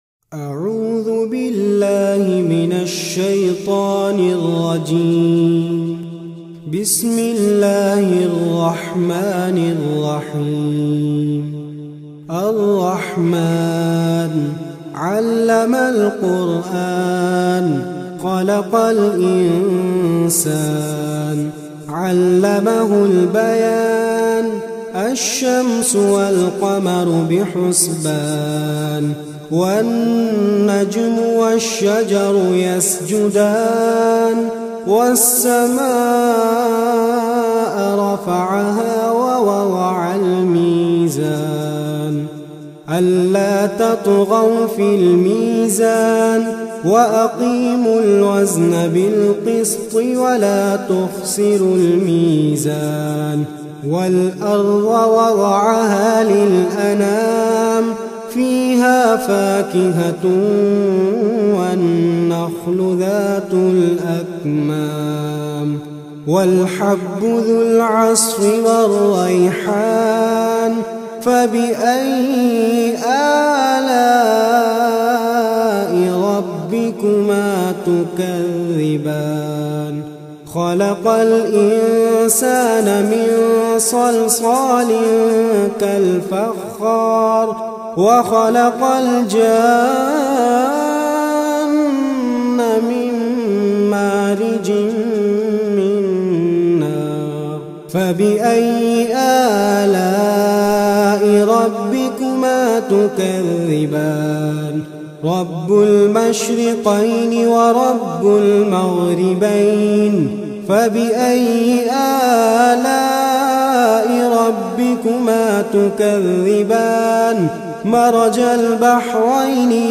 in a Heart-Touching Voice